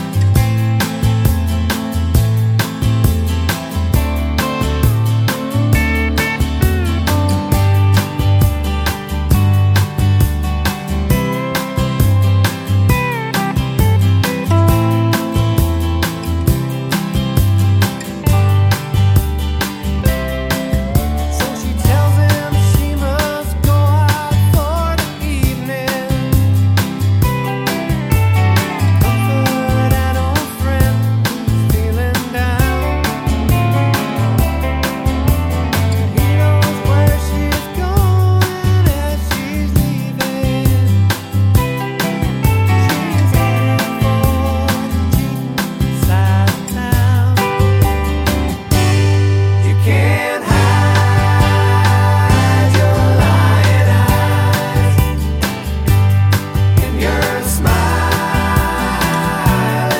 no Backing Vocals Soft Rock 6:18 Buy £1.50